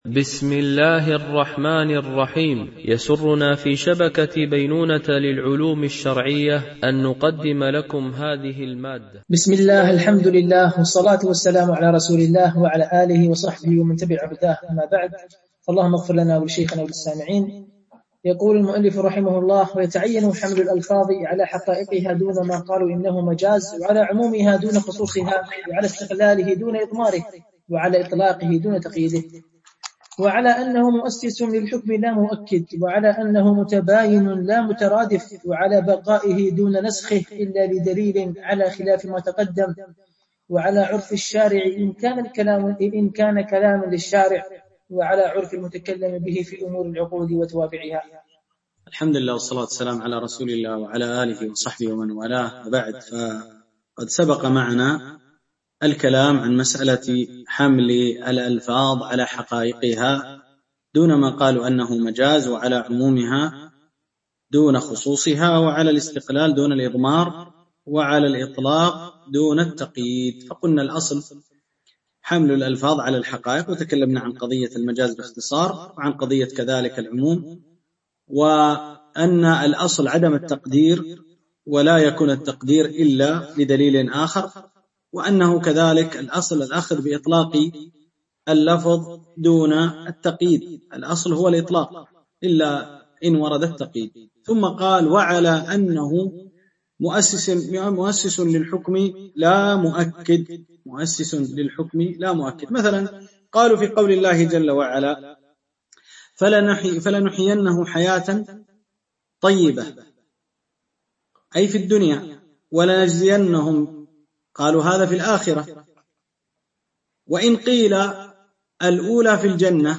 دورة علمية عن بعد
التنسيق: MP3 Mono 22kHz 32Kbps (CBR)